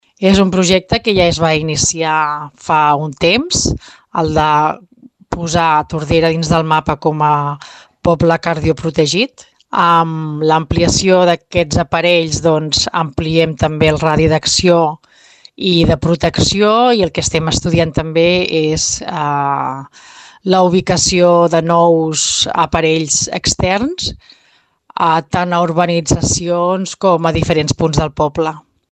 Amb l’entrega d’aquests dispositius a totes les escoles, Tordera fa un pas més per convertir-se en municipi cardioprotegit de referència. Ho explica la regidora Nàdia Cantero.